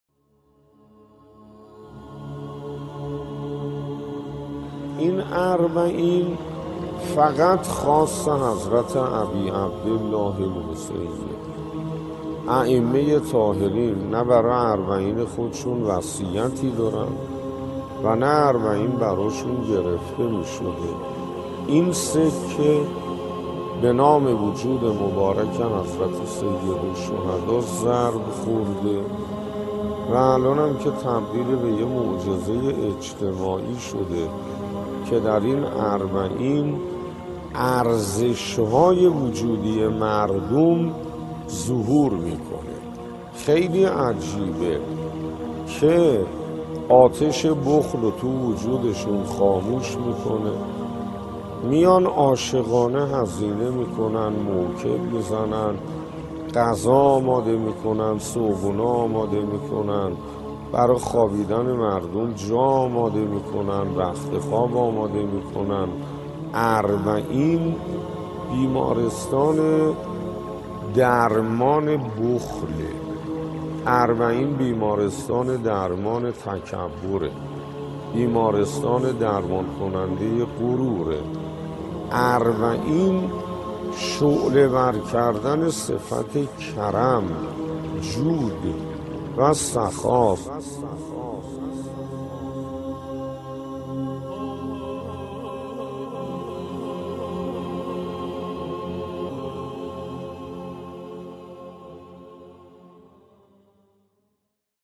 تهران_الکوثر: سخنرانی کوتاه و زیبا از استاد حسین انصاریان درباره اربعین حسینی و نقش آن در بروز و ظهور ارزش های وجودی انسان